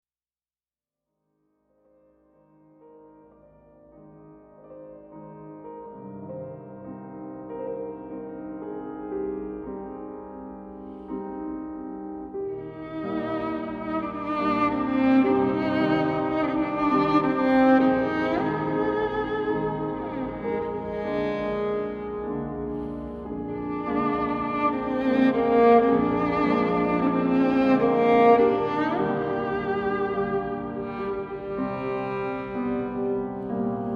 ポルトガル、聖母マリアの奇蹟、シスター・ルシアに導かれ、カルメロ修道院で奇跡の録音！